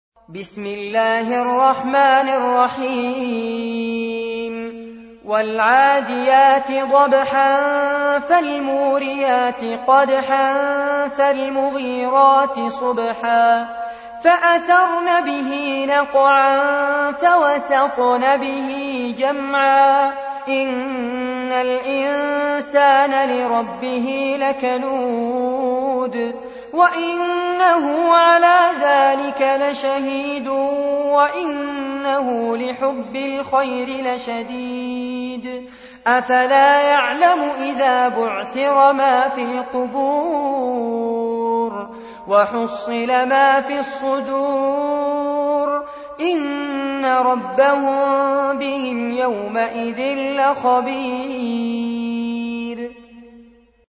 أحد أشهر قراء القرآن الكريم في العالم الإسلامي، يتميز بجمال صوته وقوة نفسه وإتقانه للمقامات الموسيقية في التلاوة.
تلاوات المصحف المجود